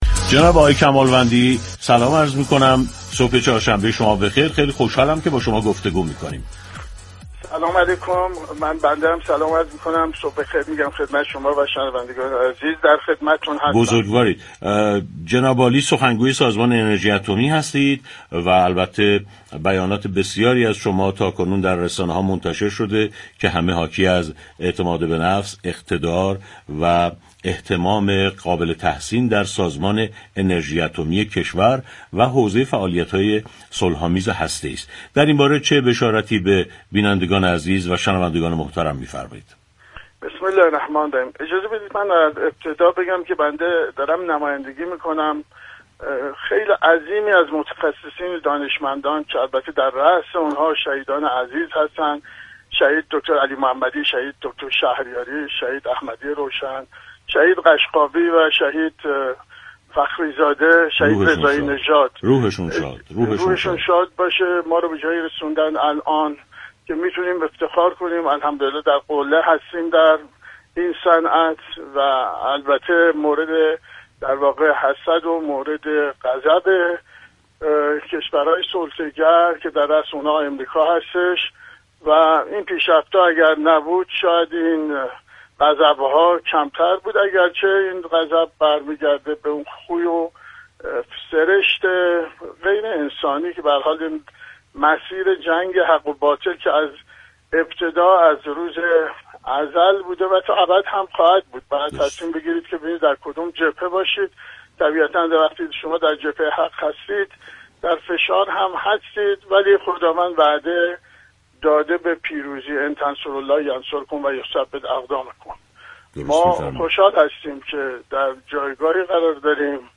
خوشحالیم در جایگاهی قرار داریم كه موجب خشم و غضب دشمن شده است به گزارش پایگاه اطلاع رسانی رادیو تهران، بهروز كمالوندی سخنگوی سازمان انرژی اتمی در گفت و گو با «سعادت آباد» اظهار داشت: خوشحالیم در جایگاهی قرار داریم كه موجب خشم و غضب دشمن شده است.